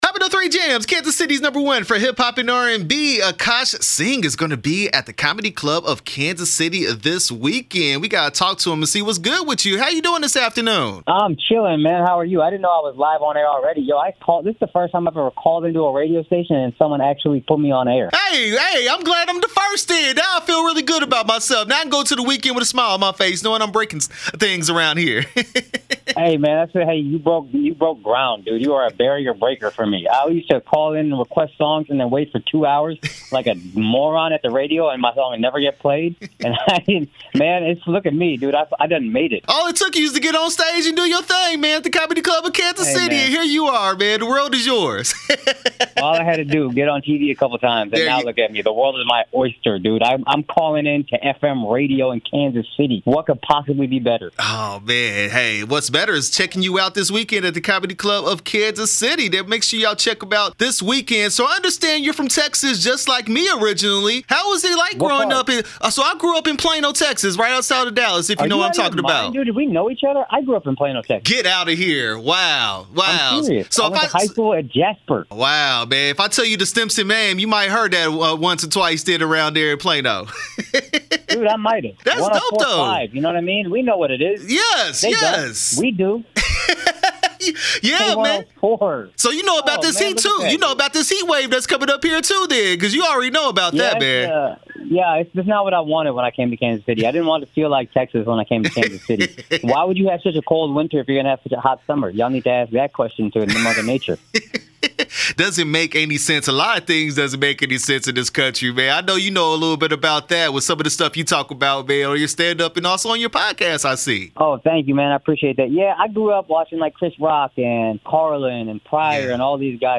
Akaash Singh Comedy Club Of Kansas City interview 6/18/21